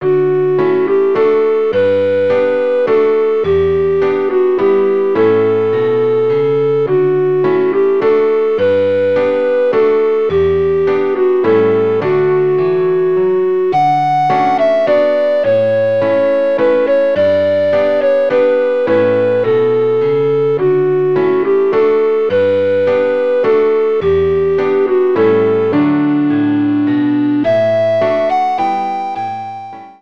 Neue Musik
Ensemblemusik
Duo
Blockflöte (1), Klavier (1)